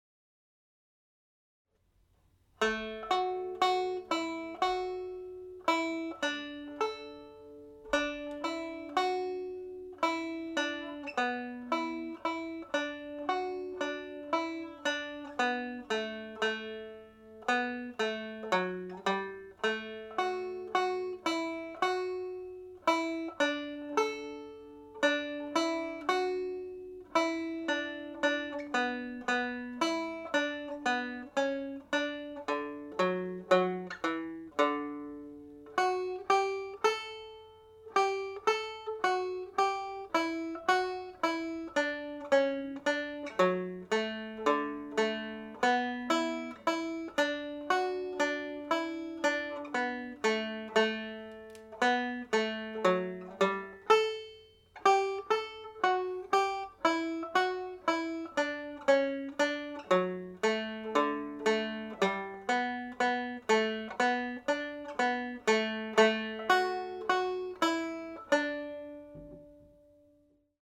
Belfast Trad Advanced class (Mid-Term Break) practice tune
The Air Tune is played as a slow reel in the key of D major.
The Air Tune played slowly